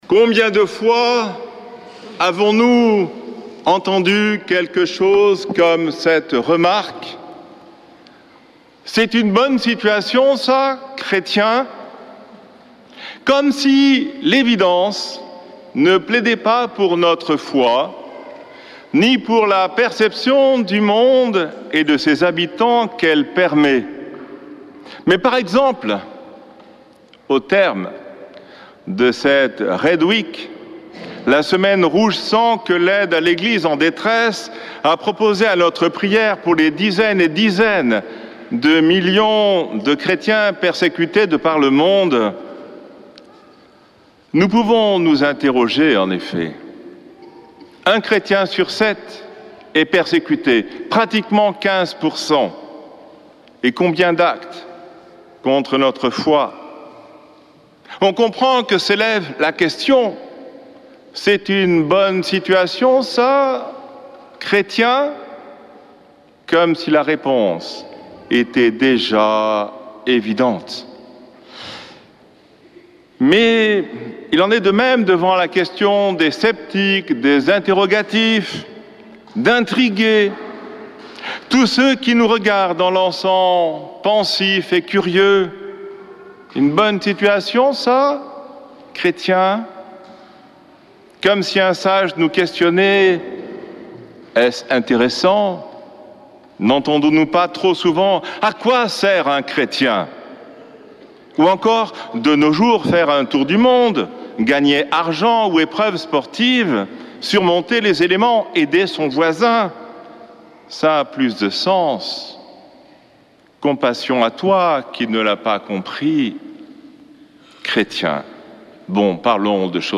dimanche 24 novembre 2024 Messe depuis le couvent des Dominicains de Toulouse Durée 01 h 30 min